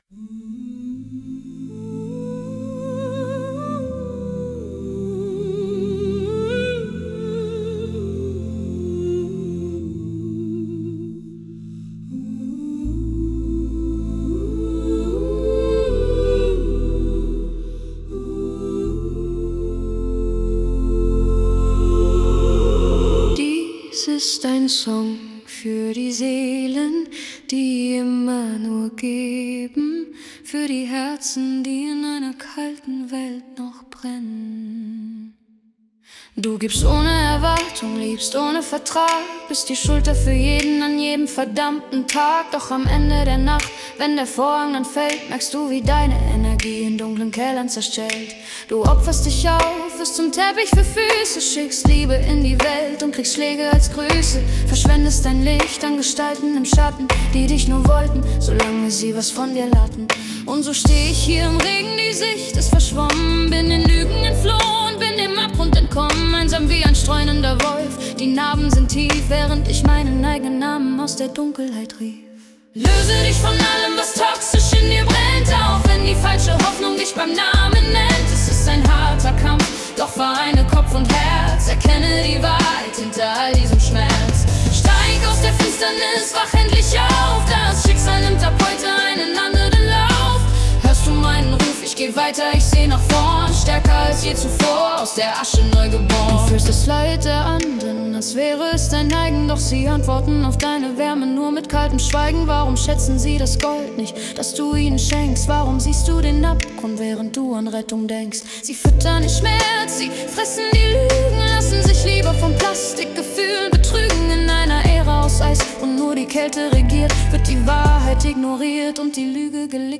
Pop, Choir, Mystery